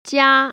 [jiā] 지아